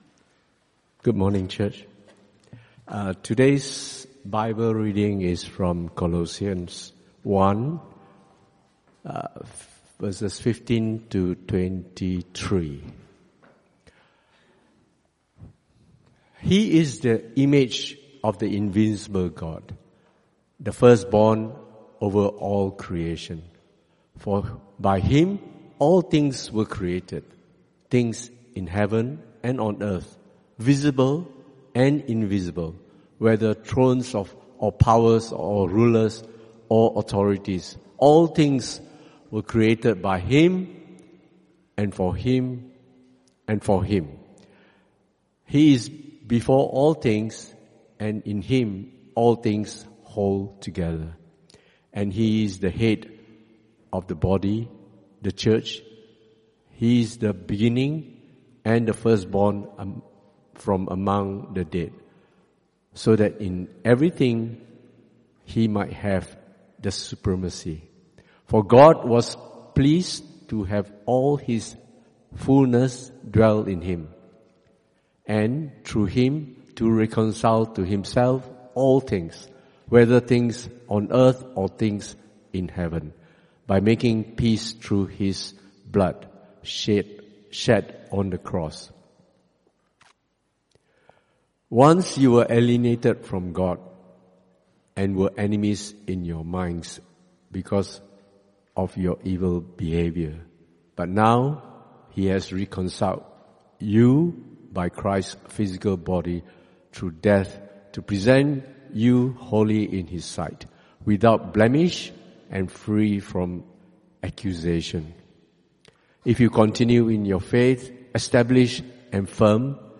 Passage: Colossians 1:15-23 Type: Sermons CBC Service